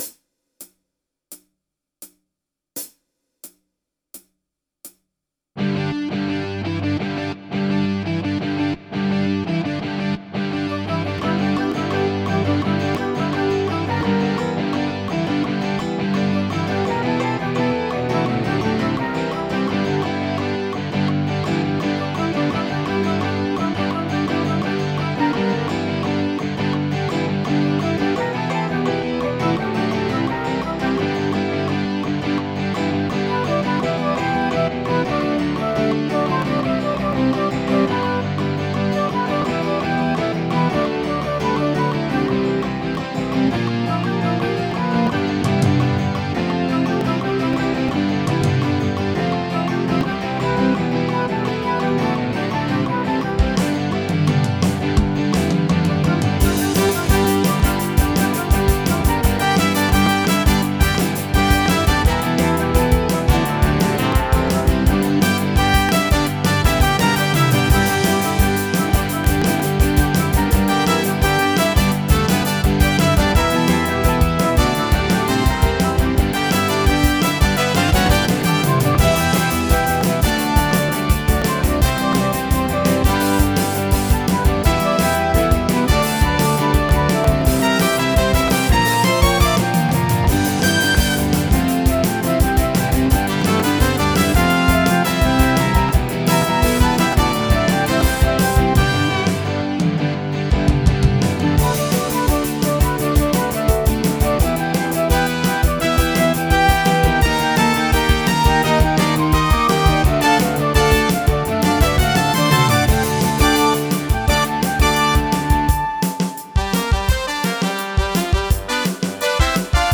multi-track instrumental version